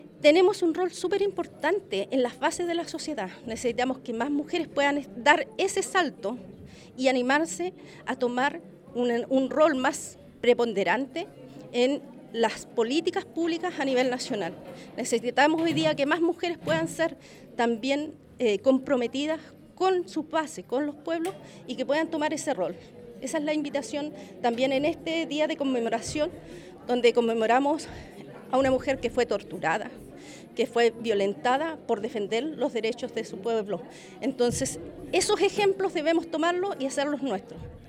La Subsecretaria de la Mujer y Equidad de Género, Luz Vidal Huiriqueo, quien participó de la primera charla del encuentro internacional resaltó la importancia de entregar espacios para las mujeres indígenas, para comprender las necesidades de los distintos territorios.